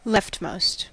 Ääntäminen
Ääntäminen US Tuntematon aksentti: IPA : /lɛft.moʊst/ IPA : /lɛft.məʊst/ Haettu sana löytyi näillä lähdekielillä: englanti Leftmost on sanan left superlatiivi.